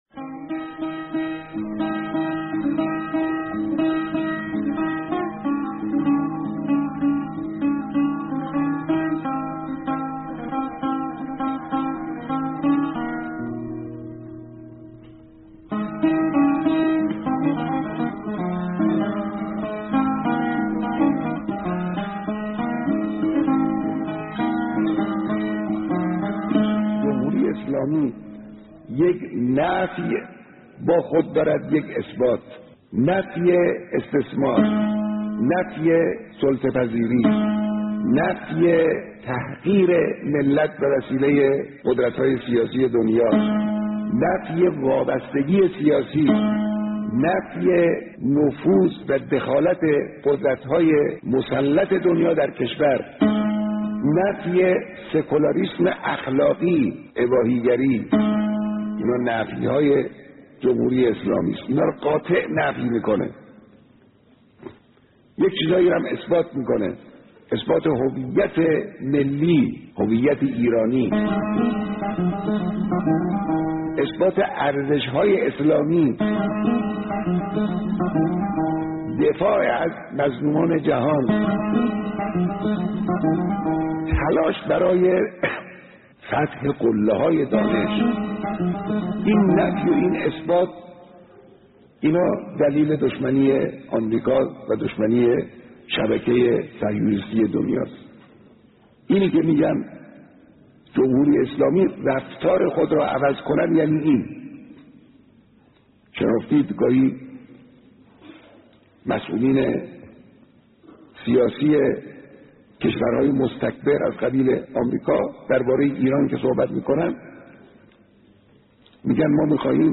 نفی‌ها و اثبات‌های جمهوری اسلامی؛ گزیده بیانات رهبر انقلاب در دانشگاه علم و صنعت